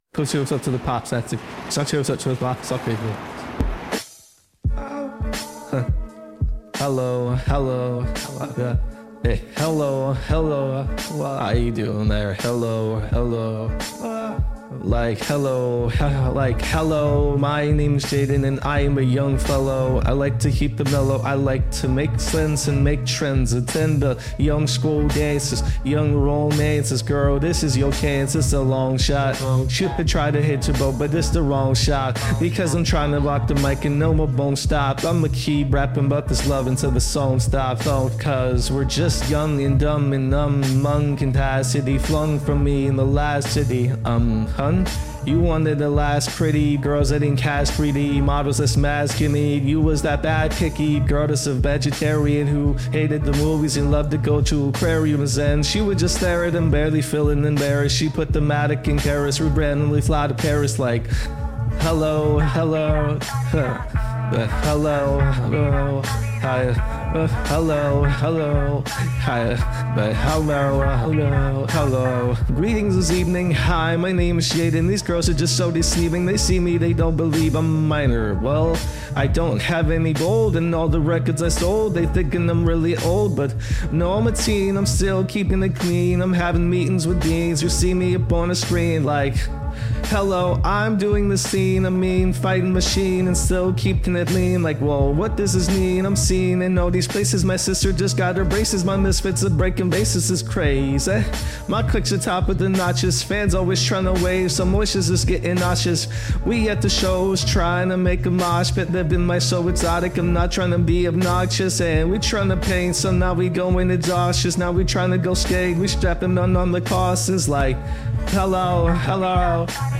I have combined Suno and RVC together.